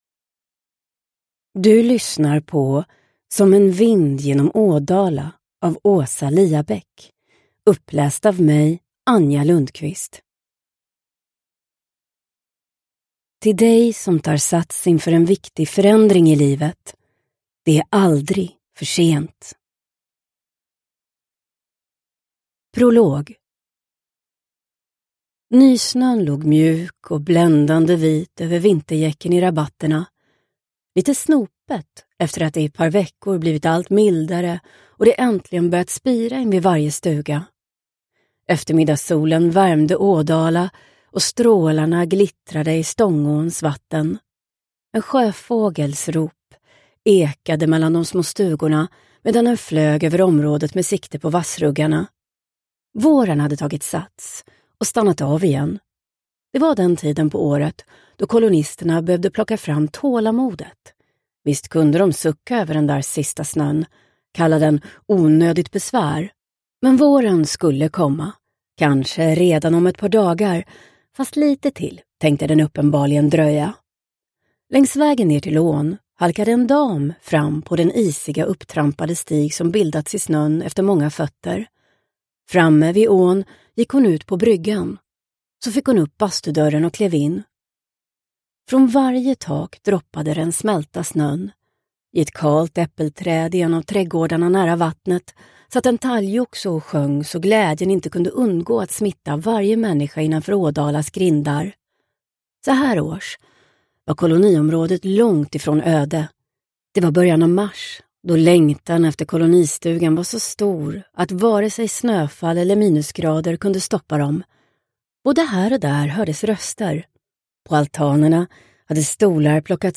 Som en vind genom Ådala – Ljudbok – Laddas ner